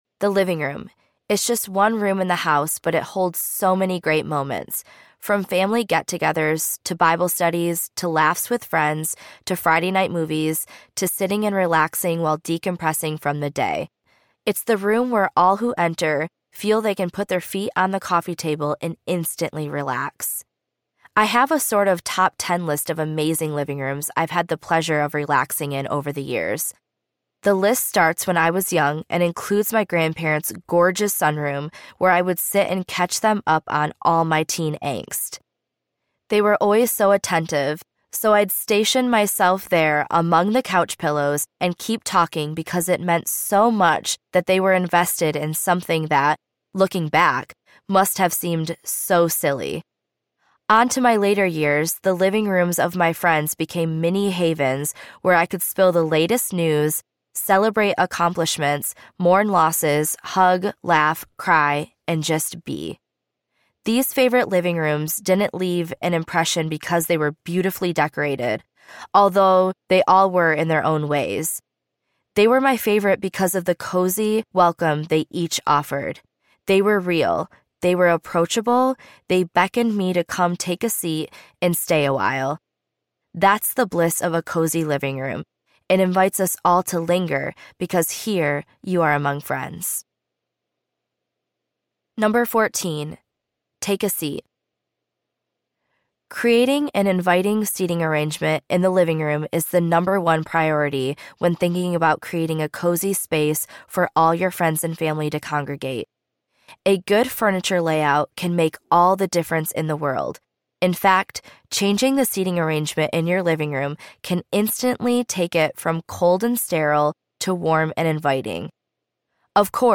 Cozy White Cottage Audiobook
2.9 Hrs. – Unabridged